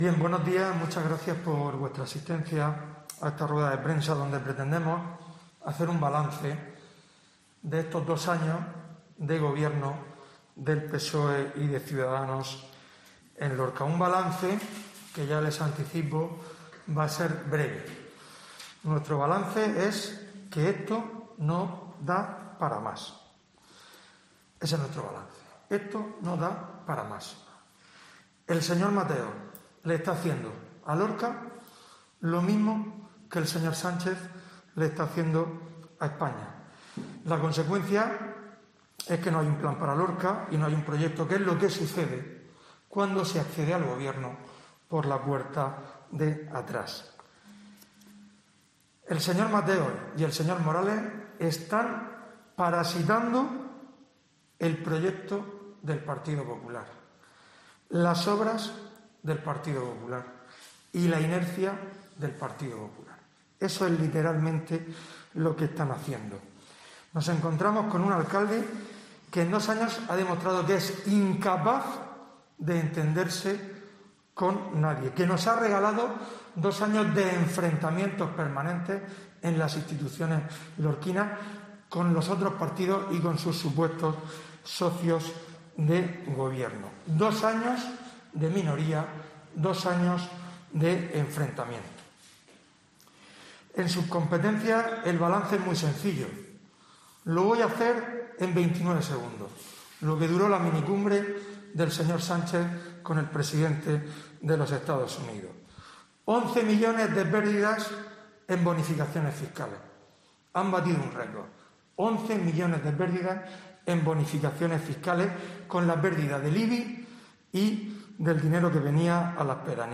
Fulgencio Gil, portavoz del PP (rueda prensa íntegra)